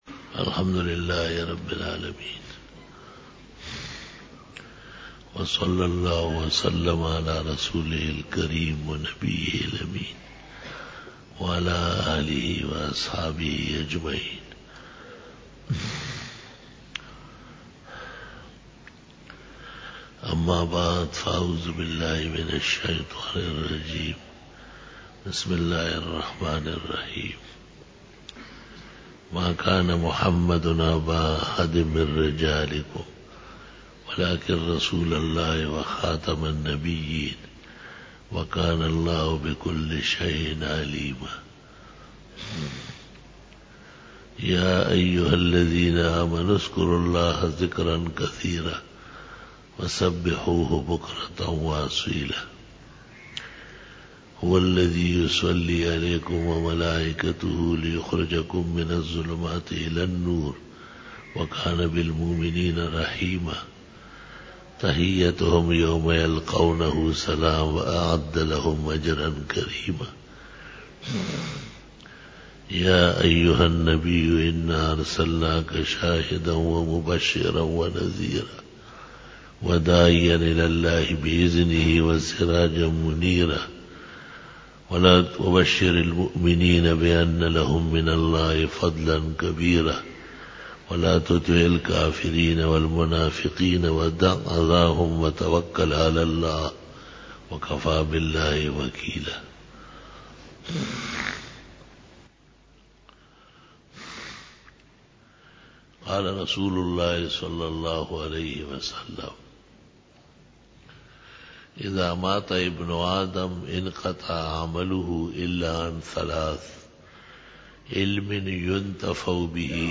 04_BAYAN E JUMA TUL MUBARAK (23-JANUARY-2015) (02 RabiUlSaani 1436h)